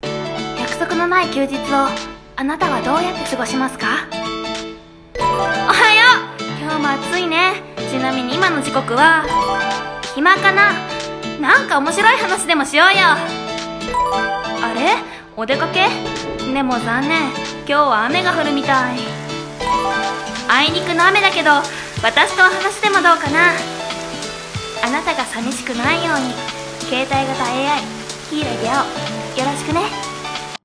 【企業CM風声劇】あなたと共に。